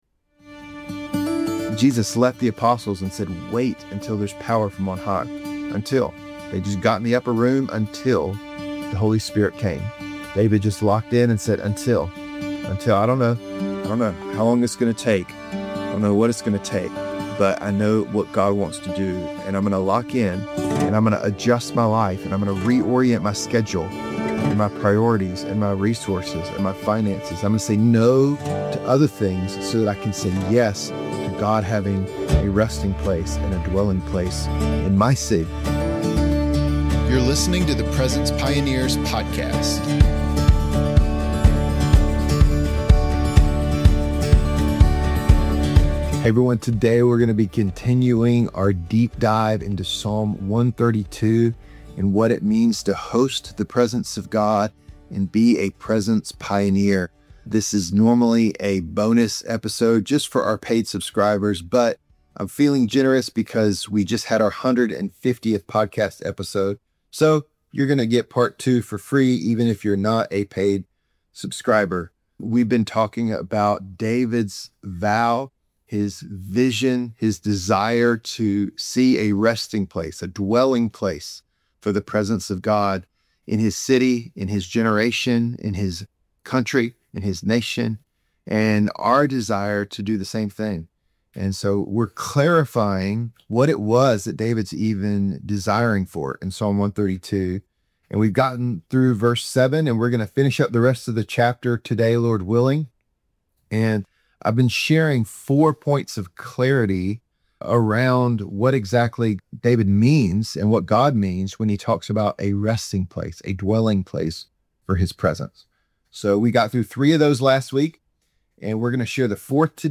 Helping worshipers, intercessors and leaders experience and host the presence of God - because God's presence changes everything. Featuring interviews with leaders of worship & prayer ministries and Bible teachings on topics such as prophetic worship, intercessory prayer, global missions, unity in the Church, revival and the tabernacle of David.